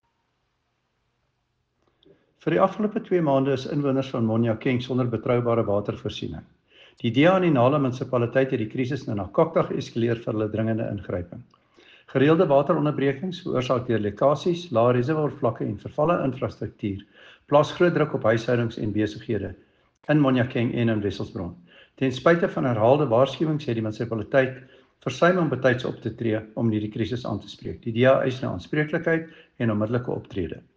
Afrikaans soundbites by Cllr David Ross and